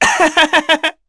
Neraxis-Vox_Happy2_kr.wav